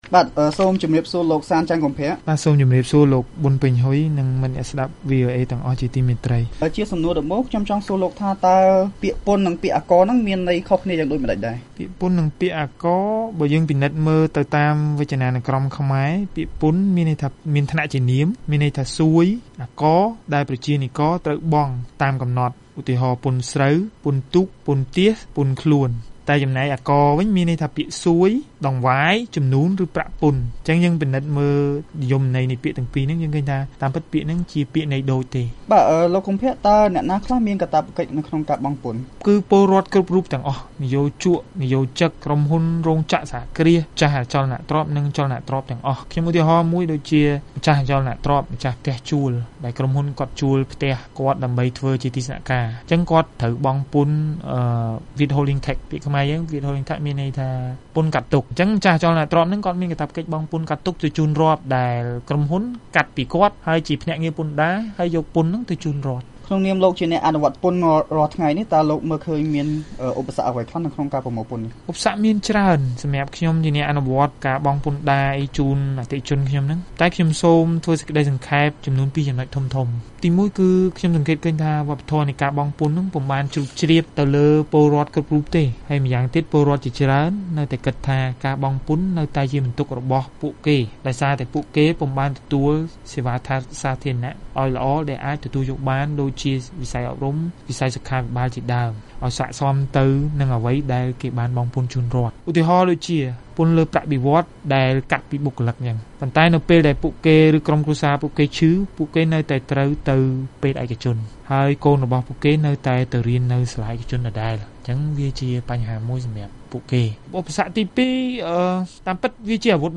បទសម្ភាសន៍ VOA៖ អ្នកជំនាញពន្ធដារថា ការបង់ពន្ធជូនរដ្ឋជាការស្នេហាជាតិ